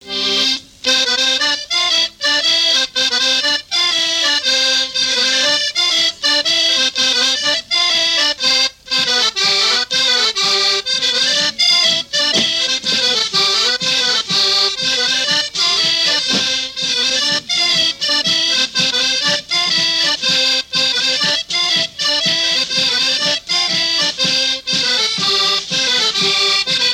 danse : branle
Pièce musicale inédite